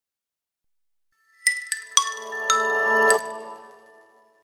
startup_tone.mp3